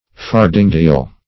Search Result for " fardingdeal" : The Collaborative International Dictionary of English v.0.48: Fardingdeal \Far"ding*deal\, n. [See Farthing , and Deal a part.] The fourth part of an acre of land.